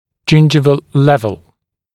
[‘ʤɪnʤɪvəl ‘lev(ə)l] [ʤɪn’ʤaɪvəl][‘джиндживэл ‘лэв(э)л] [джин’джайвэл]уровень десны